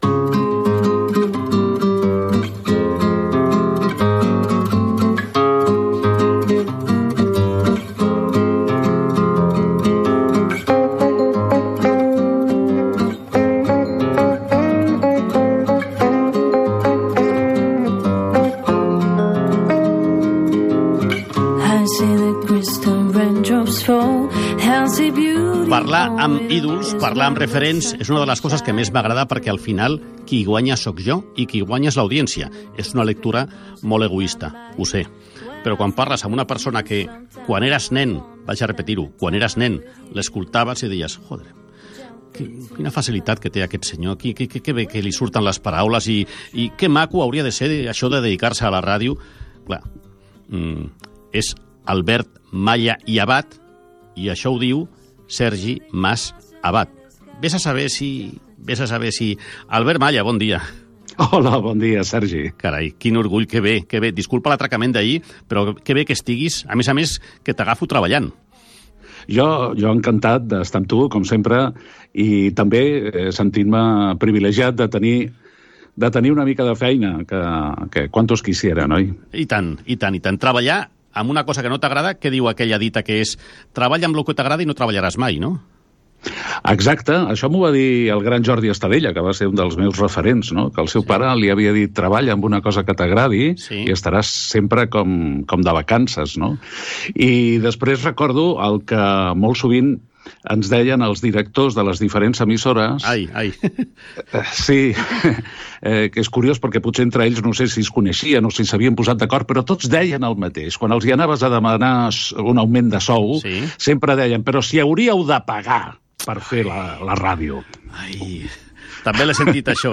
Entrevista
Entreteniment
FM